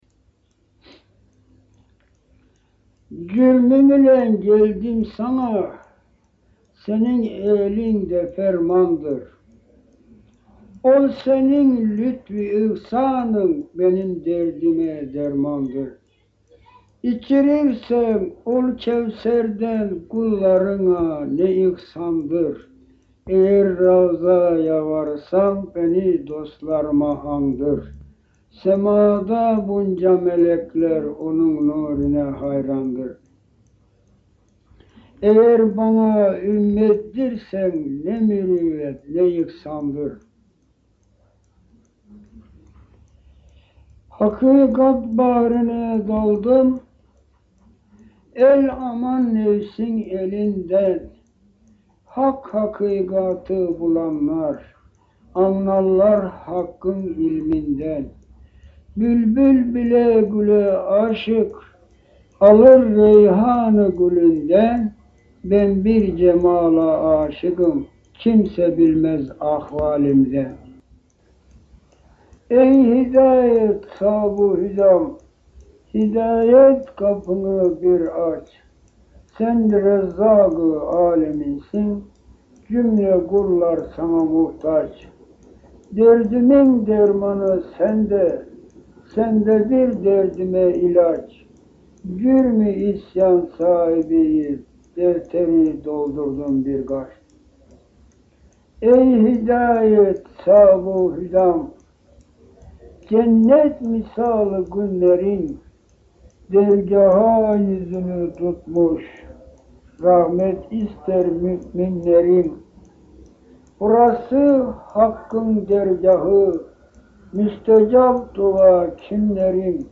İlahiler